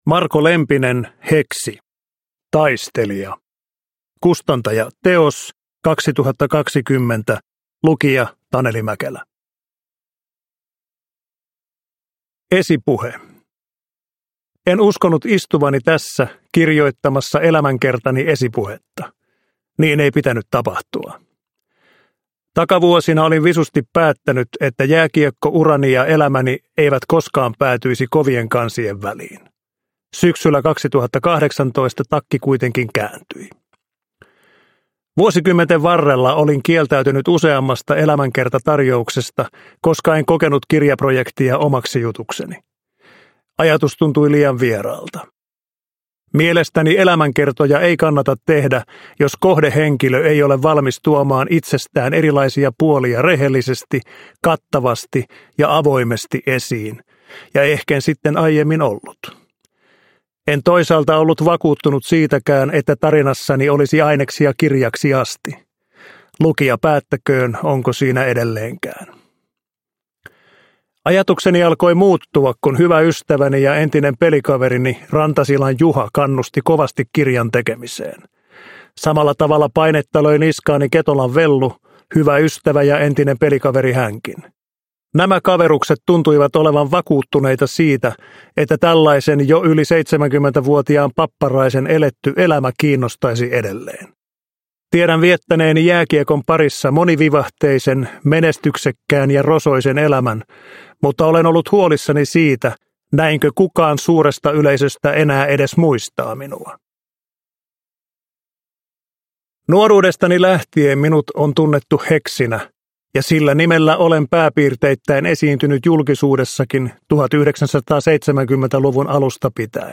Hexi - Taistelija – Ljudbok – Laddas ner
Uppläsare: Taneli Mäkelä